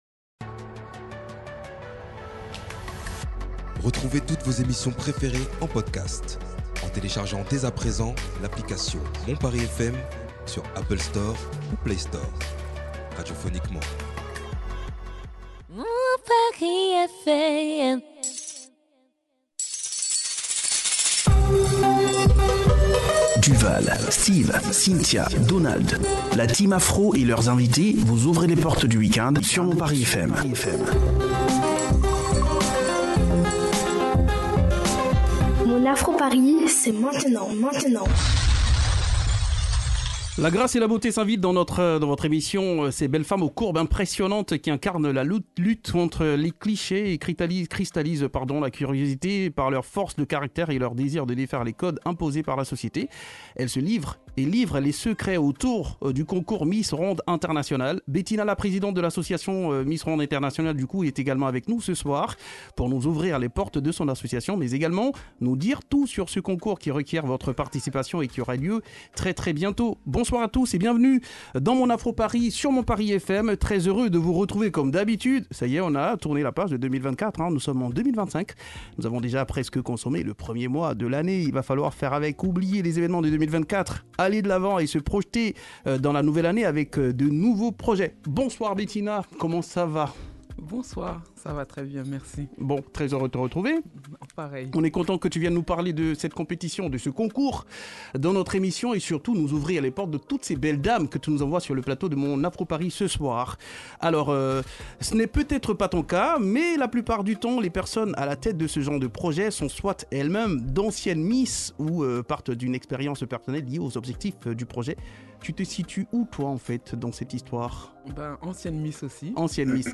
Une émission spéciale agrémentée de musique, et des jeux pour mieux connaître les candidates dans une ambiance toujours bon enfant.